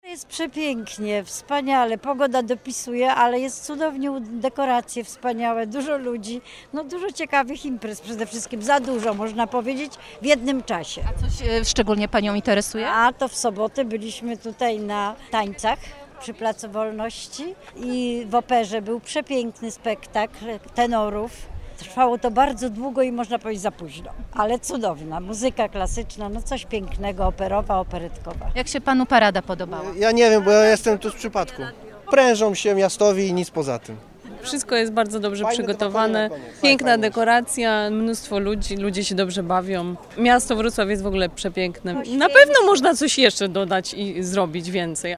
Zapytaliśmy mieszkańców, jak podobają im się tegoroczne obchody.